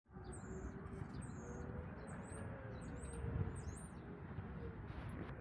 Den Gesang des Stars konnte ich sogar aufnehmen. Leider ist die Aufnahme nicht besonders gut geworden.
Stare in Berlin